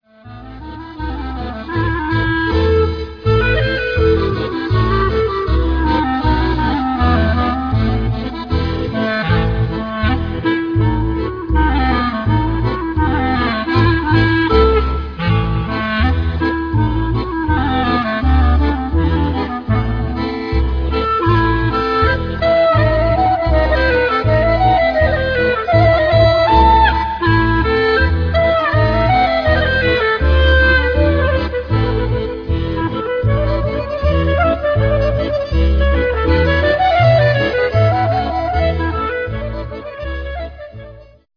utwór taneczny
klarnet clarinet
akordeon accordion
kontrabas double-bass